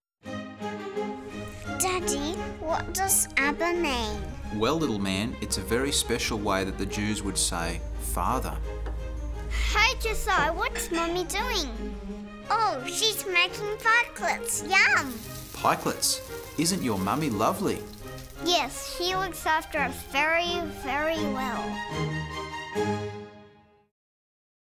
22-Who-Can-Find-a-Virtuous-Woman-dialogue.mp3